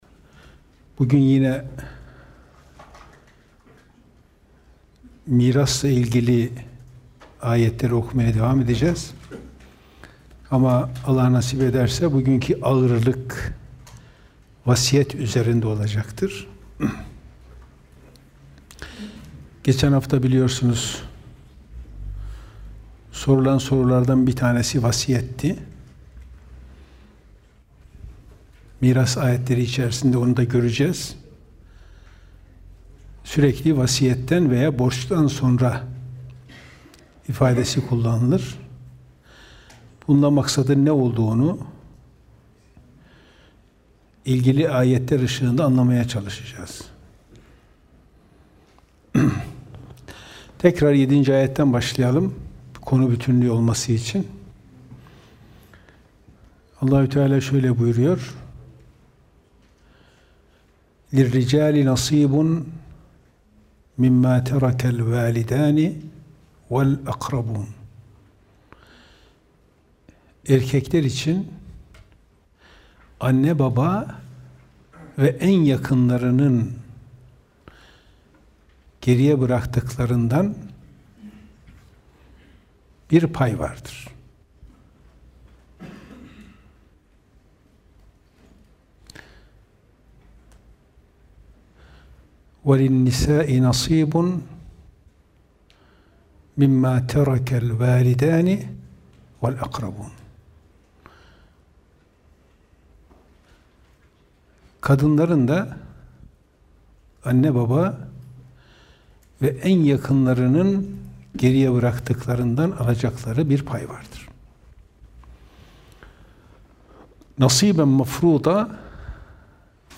Gösterim: 2.387 görüntülenme Kur'an Sohbetleri Etiketleri: kuran sohbetleri > miras > nisa suresi 8. ayet > vasiyet Bugün yine mirasla ilgili ayetleri okumaya devam edeceğiz.